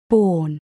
17. born: be born (v.) /bɔ:n/ sinh, đẻ